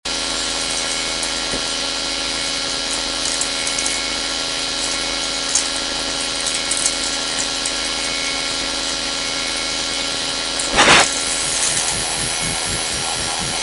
Il seguente segnale VLF è stato ricavato con un ricevitore radio VLF da 0Hz a 30000Hz (cosiddetto "largo di banda"). Premessa: il rumore di fondo che permane, lo imputiamo alla presenza della rete elettrica di alcune abitazioni.
Segnale radio VLF
28/02/2006 Campomarzo di Lendinara